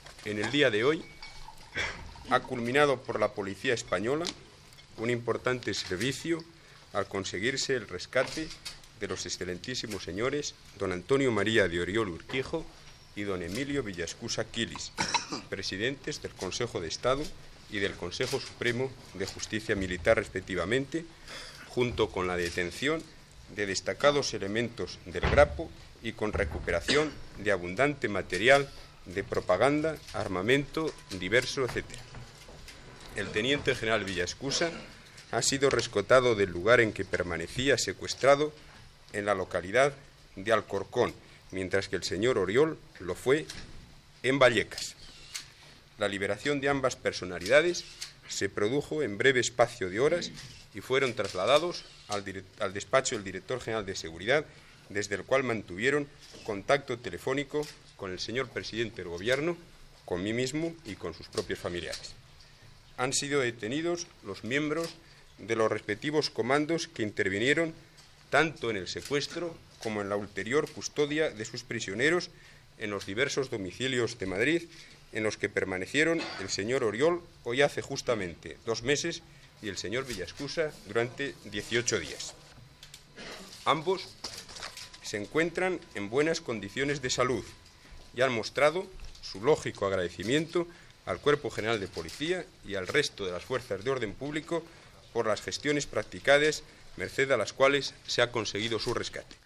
El ministre de governació espanyol Rodolfo Martín Villa informa que la policía ha alliberat Antonio María de Oriol, president del Consell d'estat i al tinent general Villaescusa, president del consell de justícia militar, segrestats per ETA
Informatiu